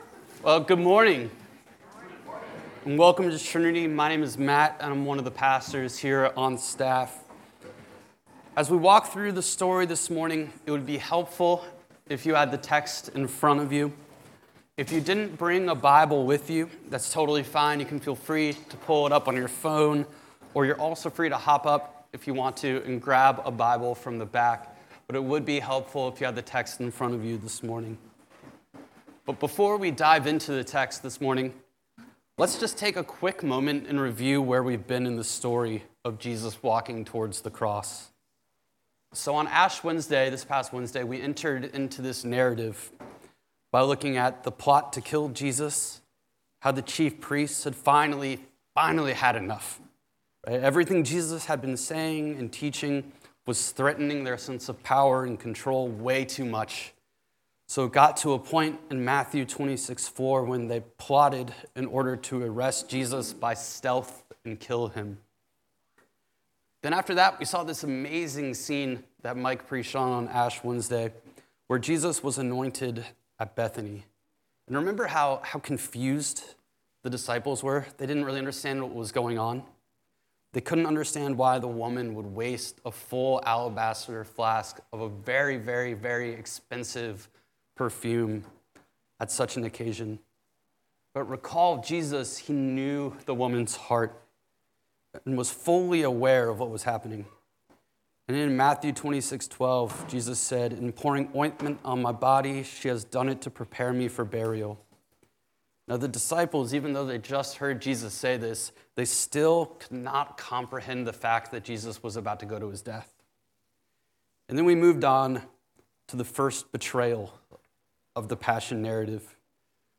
This sermon shows how Jesus, though deeply sorrowful, finds resolve and strength through worship and submission, choosing “your will be done” as redemption begins to unfold. The message calls believers to recognize their own weakness and find true strength not in themselves, but in surrendering to Jesus, who suffered, conquered sin, and now intercedes for us.